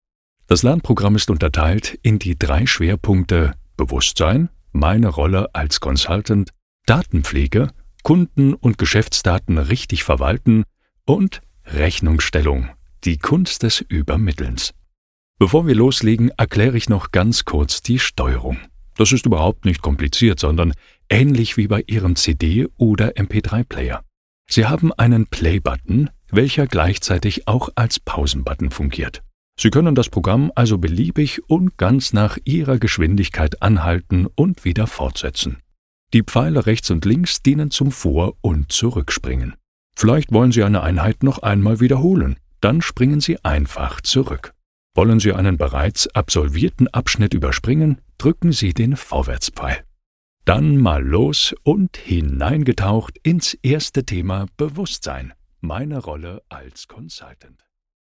E-Learning
Verbindlich